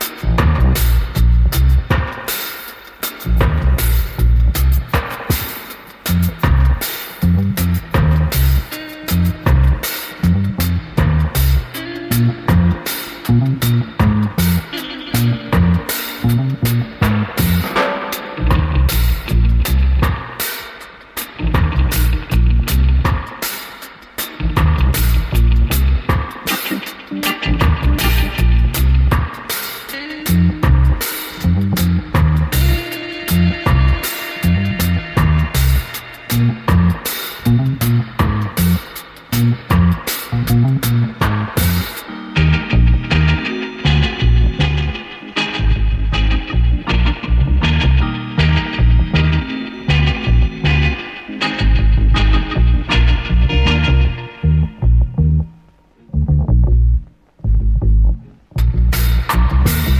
Dub Reggae